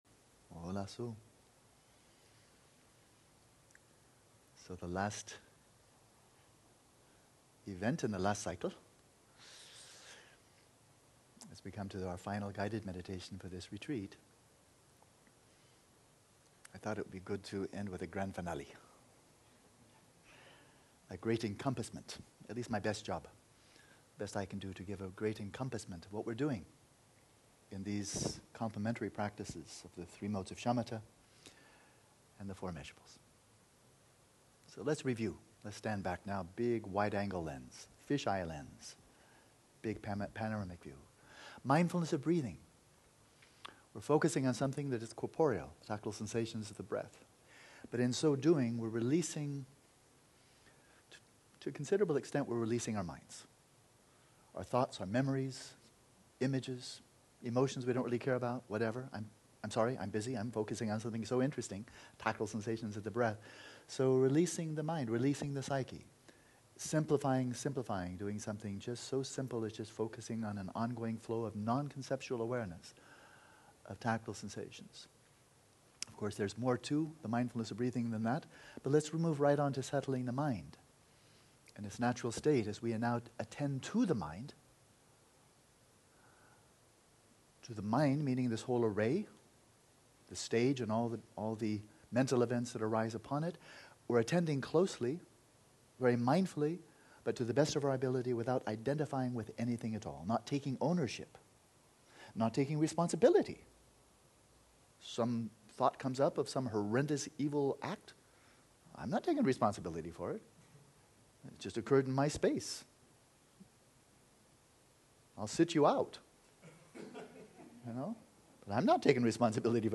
With shamatha, we withdraw inwards, away from our ordinary identification with the limitations of our physical embodiment and our coarse psyche. Then with the four immeasurables, we expand outwards to identify with all beings. While leading the meditation on equanimity, we are guided briefly through all modes of shamatha and then into the practice of tonglen.